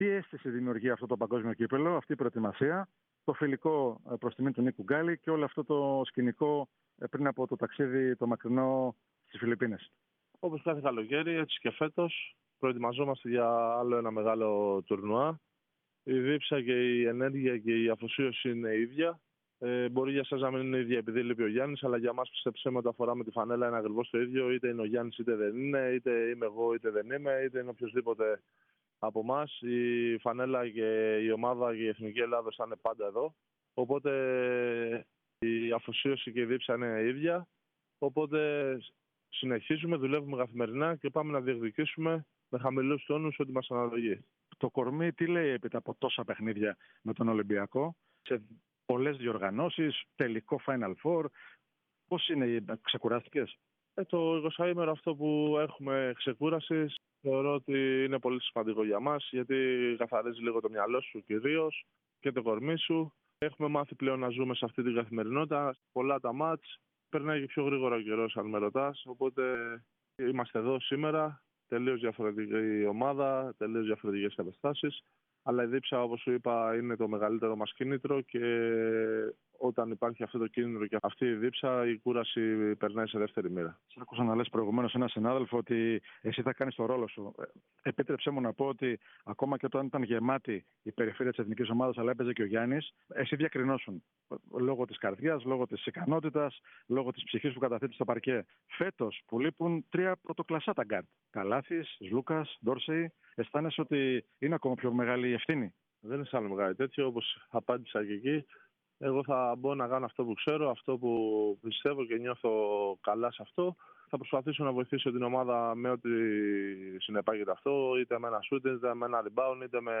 Παράλληλα, ο Γιαννούλης Λαρετζάκης μίλησε στο πλαίσιο της Media Day, σχολιάζοντας τον ρόλο του στην Εθνική ομάδα καθώς, τον Νίκο Γκάλη, ενώ τόνισε πως ο ίδιος θα συνεχίσει να κάνει αυτό που μπορεί και ξέρει καλά στον αγωνιστικό χώρο.